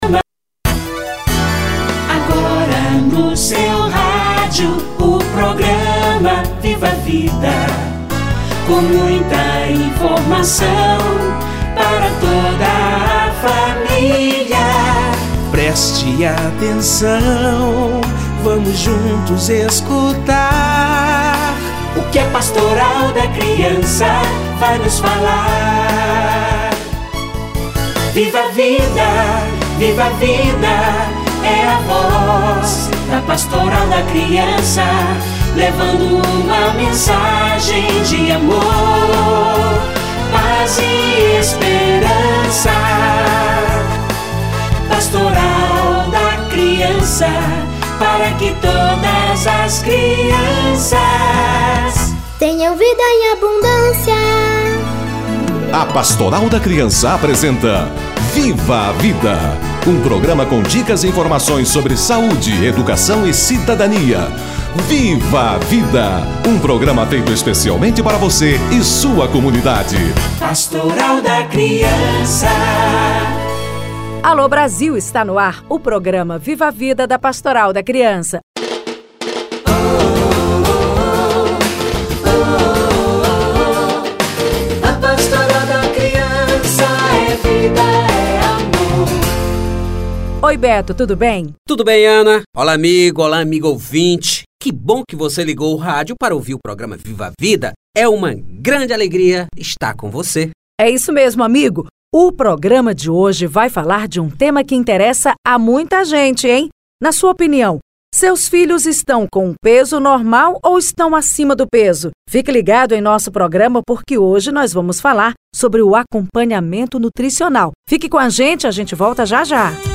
Acompanhamento nutricional - Entrevista